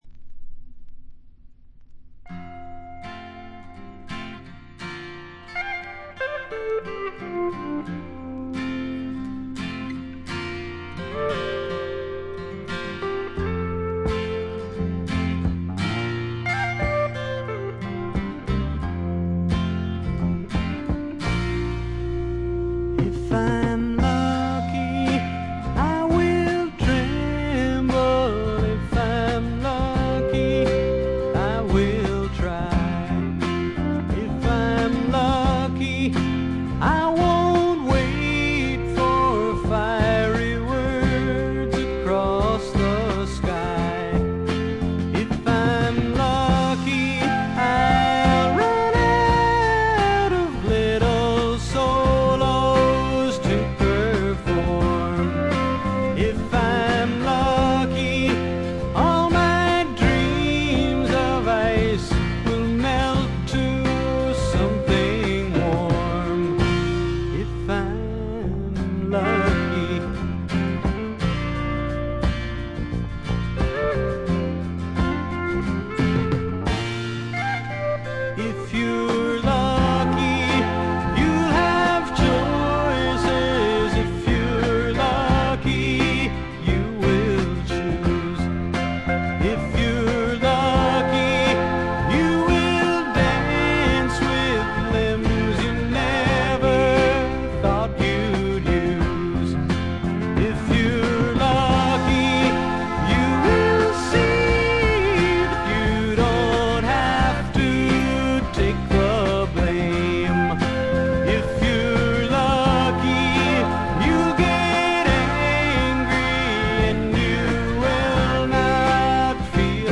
*** LP ： USA 1972
ほとんどノイズ感無し。
試聴曲は現品からの取り込み音源です。